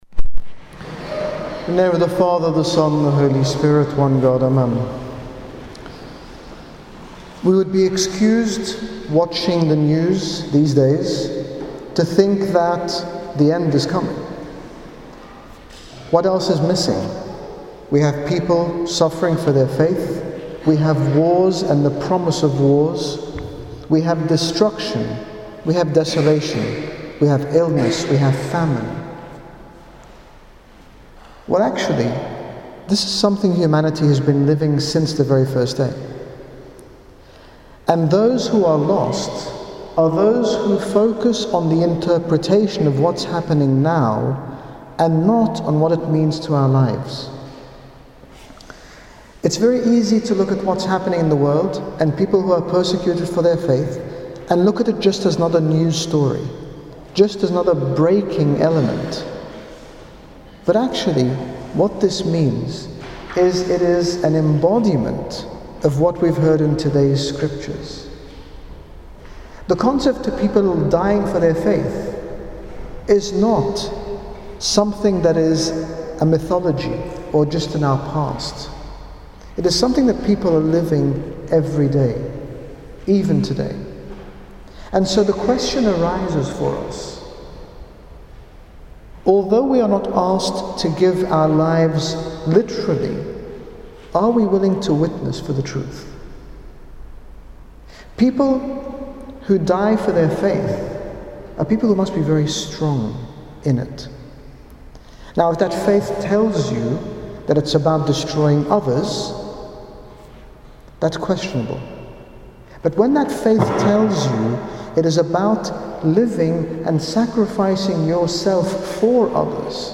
In this sermon at St Paul Ministry, His Grace Bishop Angaelos speaks about our daily witness to the Truth and explains that even if we are not called to give our lives for our Faith we are called to follow the footsteps of our Lord Jesus Christ and be clear in the message we proclaim through being selfless and living and treating others as He did.